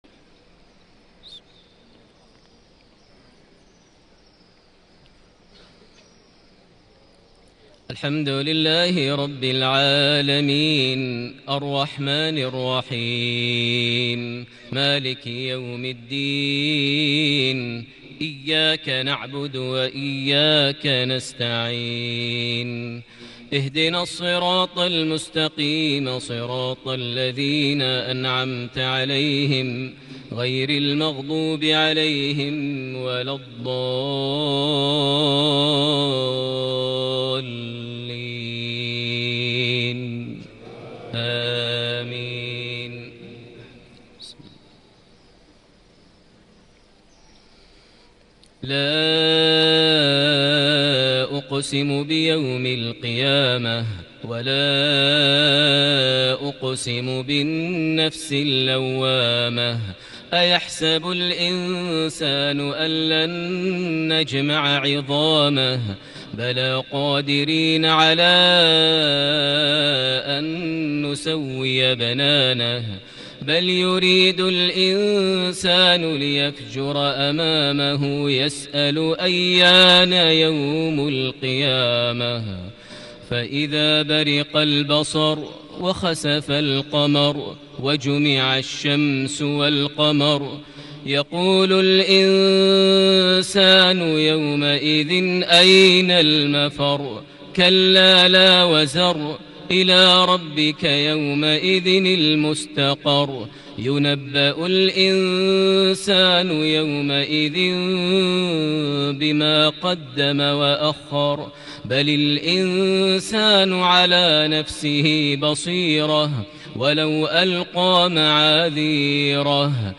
مغرب الجمعة 8-3-1440هـ سورة القيامة > 1440 هـ > الفروض - تلاوات ماهر المعيقلي